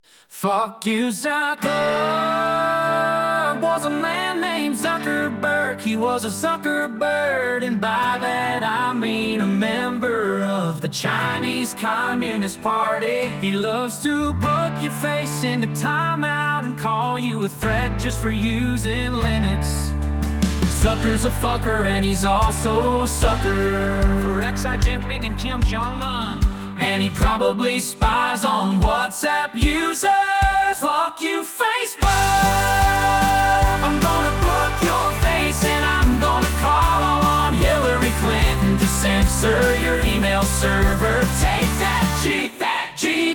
here's Microsoft office365 outlook sound for web users